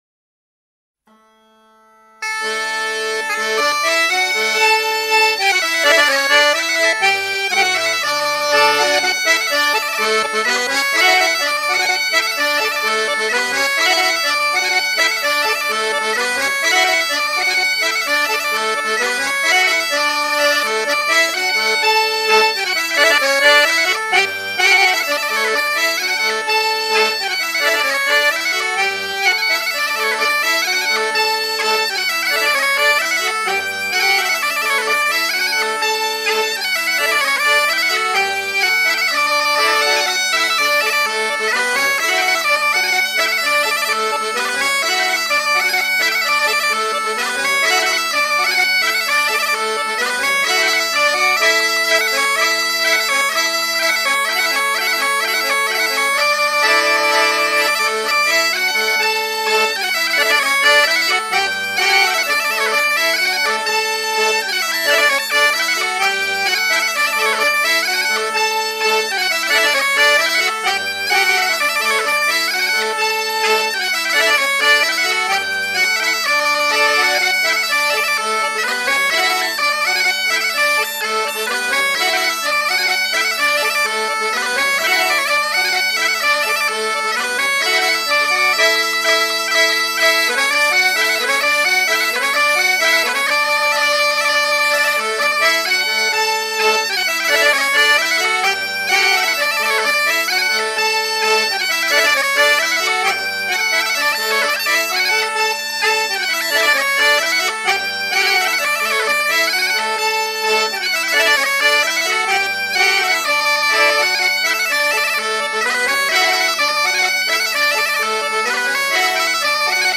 Air de grand' danse du Marais breton-vendéen
danse : ronde : grand'danse
Genre laisse